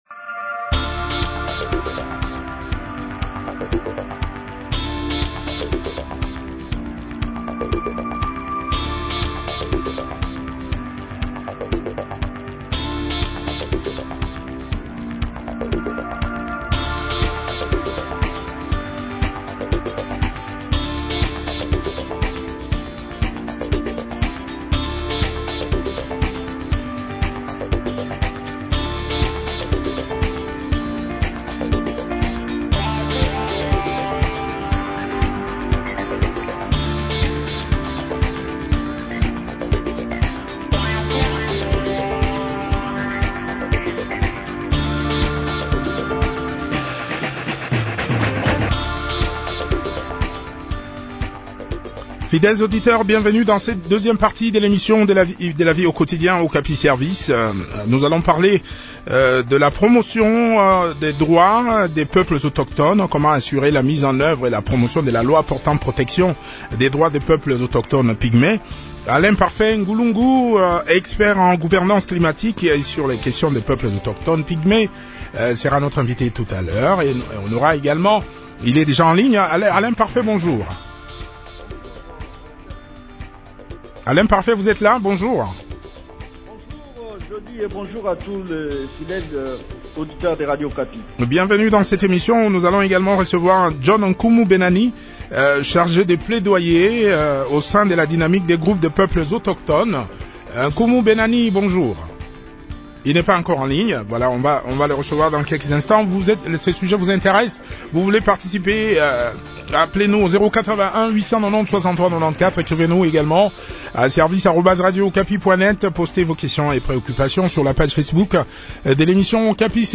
a aussi pris part à cette interview.